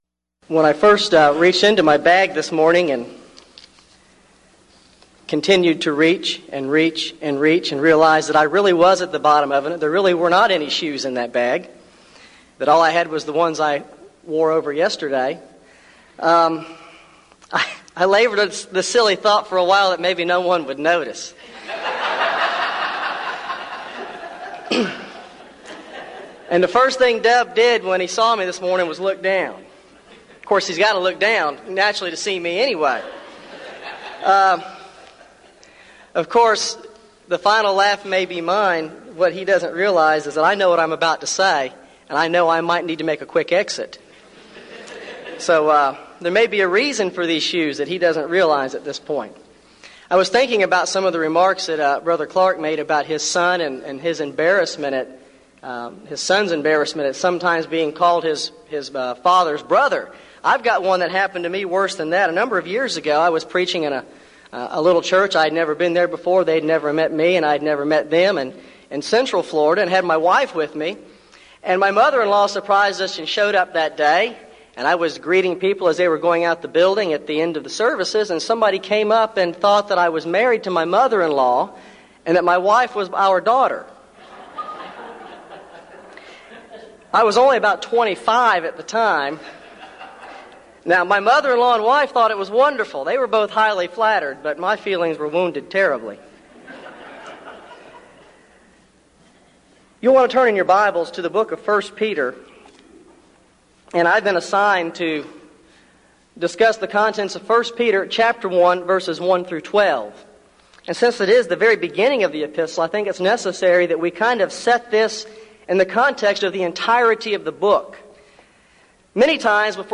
Event: 1998 Denton Lectures
lecture